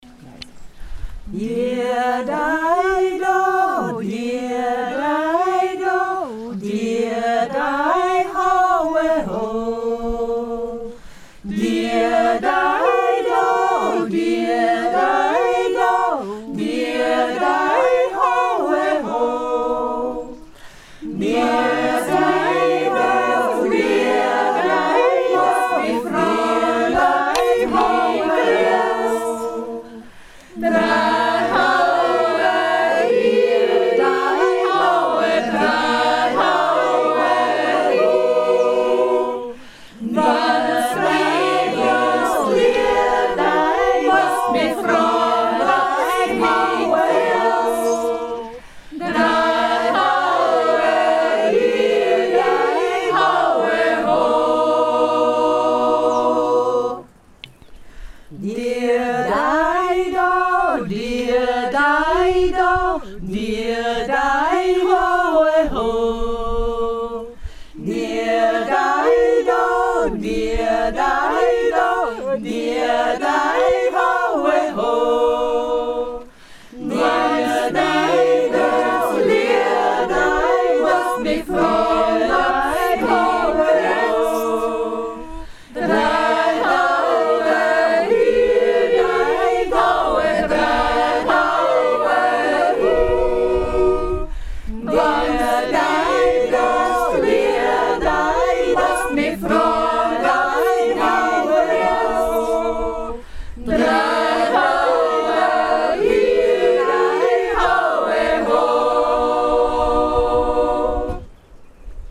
Die Gruppe jodelt
dr-busserljodler.mp3